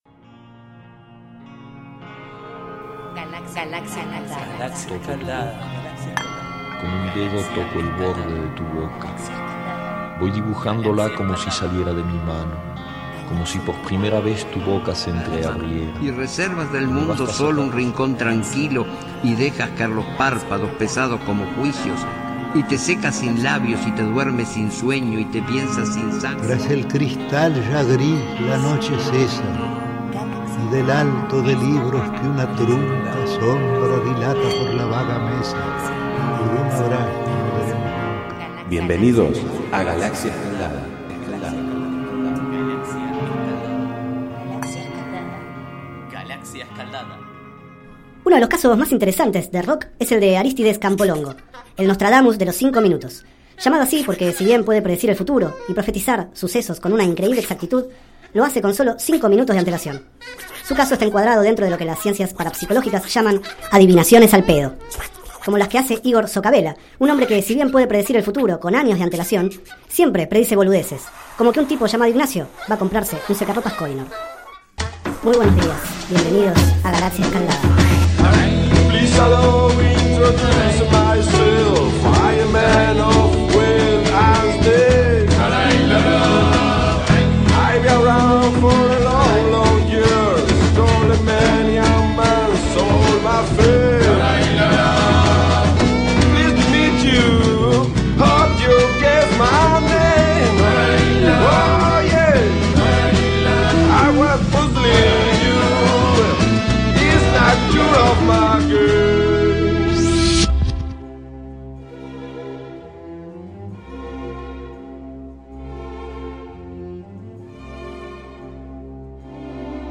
Este es el 08º micro radial, emitido en los programas Enredados, de la Red de Cultura de Boedo, y En Ayunas, el mañanero de Boedo, por FMBoedo, realizado el 25 de mayo de 2013, sobre el libro  Peter Capusotto fantástico, de Diego Capusotto y Pedro Saborido.
Durante el micro escuchamos fragmentos de My Chelsea , de Phil Minton, Lol Coxhill y Noel Akchote y del Concierto para Violín Nº 1 en La menor de Bach. También, por supuesto, el tema de los Stones cantado por Diego Capusotto que, a modo de ganzúa, abre la fantástica puerta de cada programa.